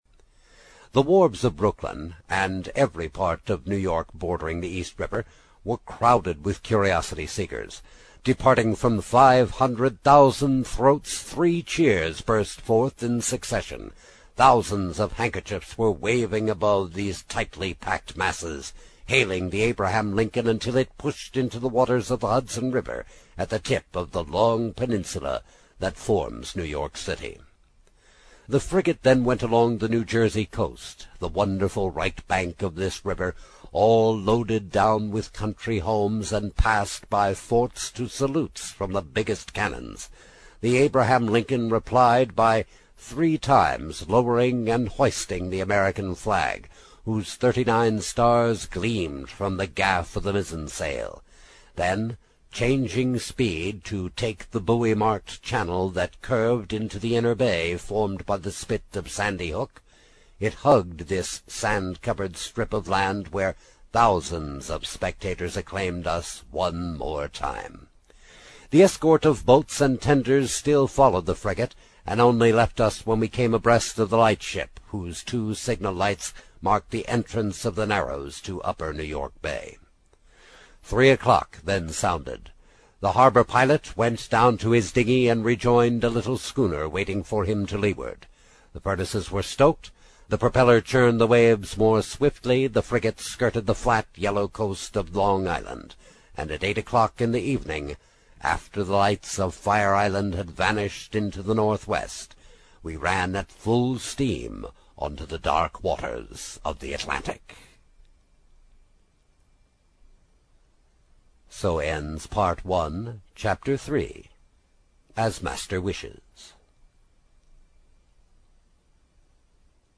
在线英语听力室英语听书《海底两万里》第31期 第3章 随您先生的便(9)的听力文件下载,《海底两万里》中英双语有声读物附MP3下载